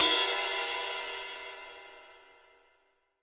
Index of /90_sSampleCDs/AKAI S-Series CD-ROM Sound Library VOL-3/16-17 CRASH